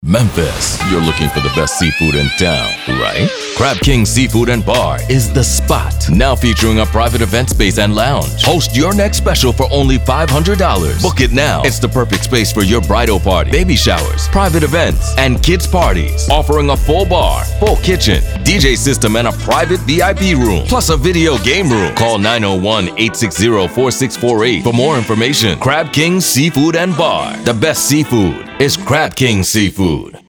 High Quality Fast Radio Ad Production